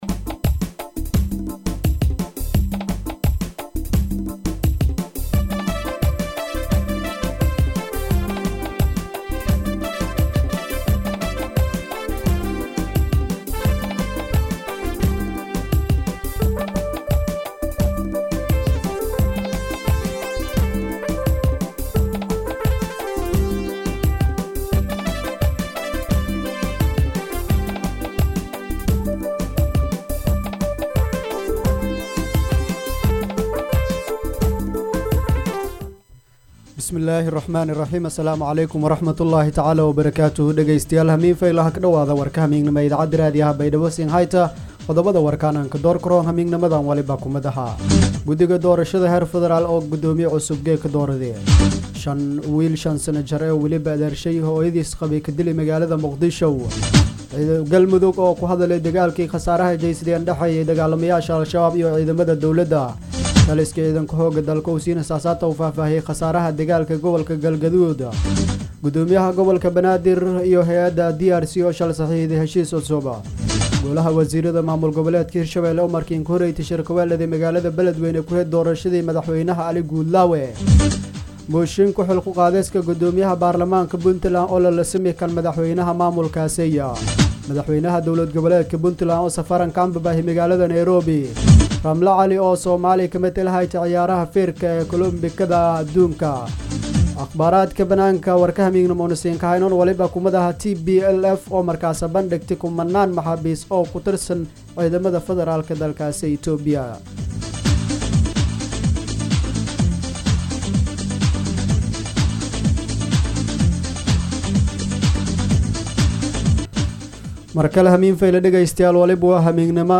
DHAGEYSO:- Warka Habeenimo Radio Baidoa 4-7-2021